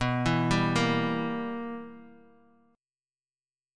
BM7sus4#5 chord